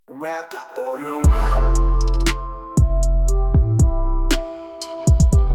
Rap，押韵